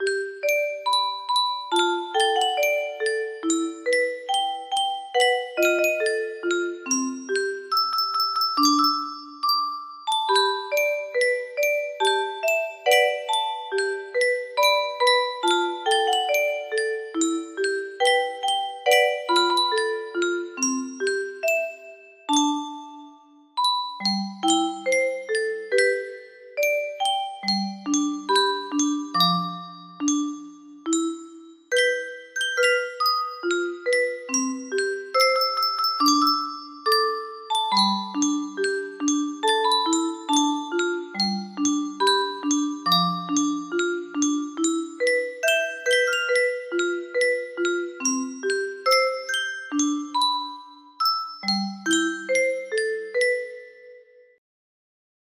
Grand Illusions 30 (F scale)
BPM 70